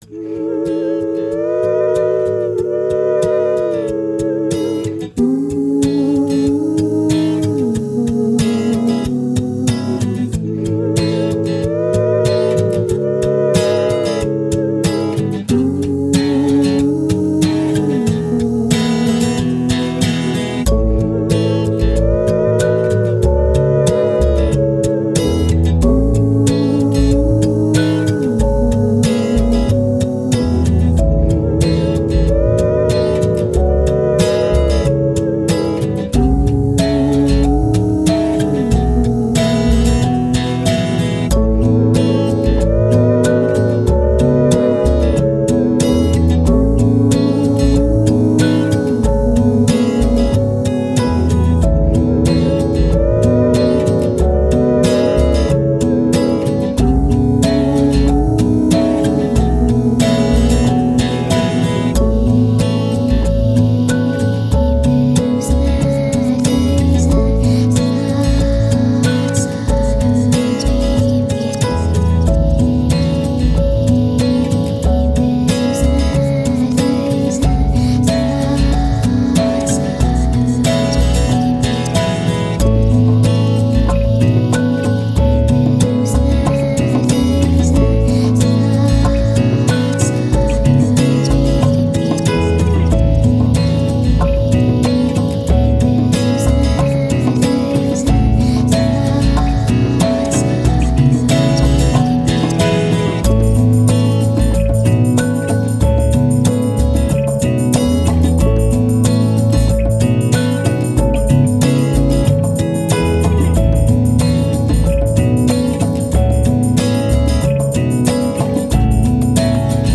Ambient, Downtempo, Haunting, Thoughtful, Hope